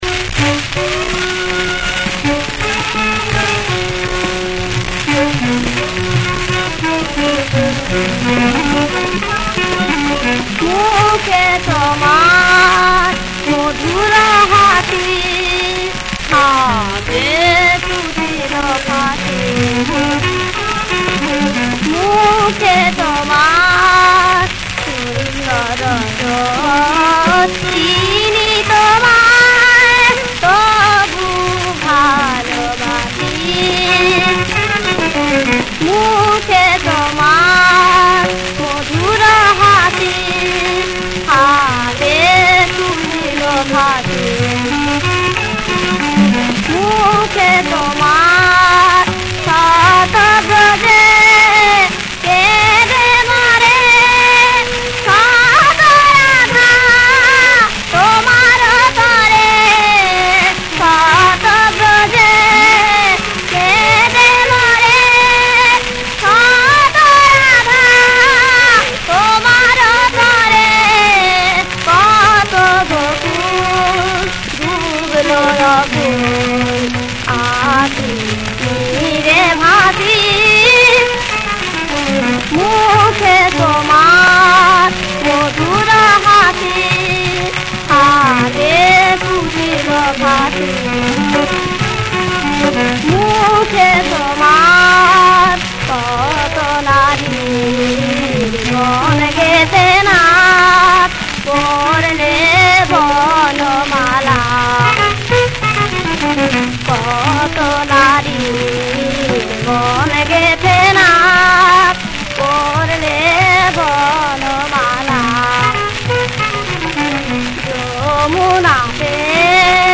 • সুরাঙ্গ: ভজন
• রাগ: সিন্ধু-মিশ
• তাল: কাহারবা